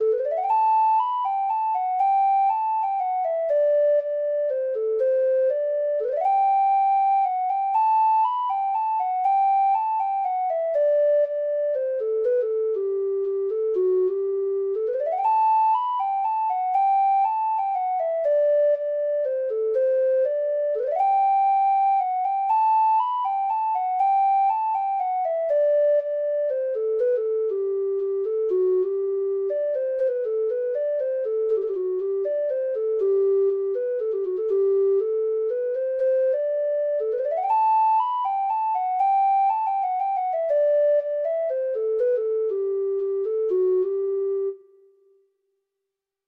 Traditional Trad. The Redhaired Man's Wife (Irish Folk Song) (Ireland) Treble Clef Instrument version
Free Sheet music for Treble Clef Instrument
Irish